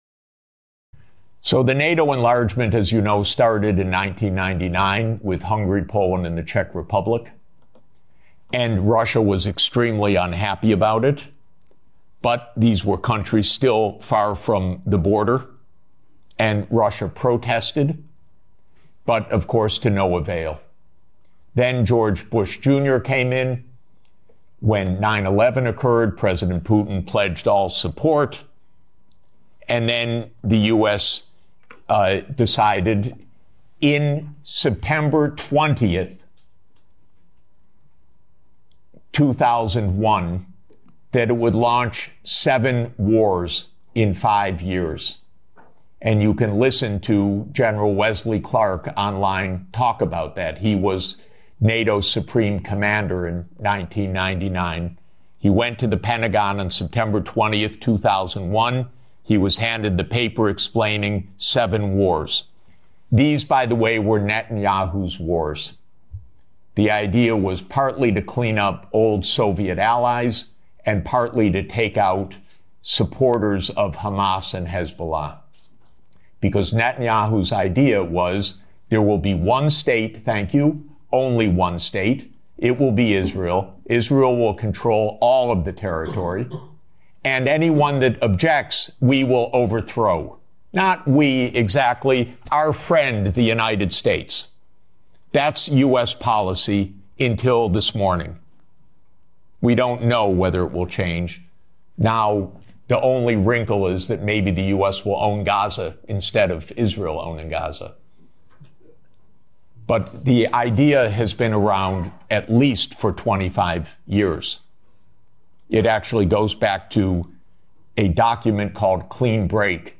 ( This is Full On ) -- Jeffrey Sachs' Explosive Address at the EU Parliament Sends Shockwaves Across Europe